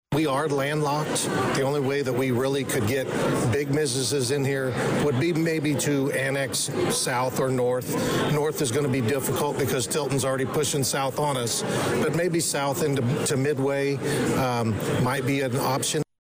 Full Crowd Comes to St. Mary’s Church for Westville Candidates Forum